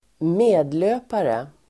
Ladda ner uttalet
medlöpare substantiv, fellow traveller , yes-man , blind follower Uttal: [²m'e:dlö:pare] Böjningar: medlöparen, medlöpare, medlöparna Definition: osjälvständig anhängare av en dominerande el. härskande riktning el. makt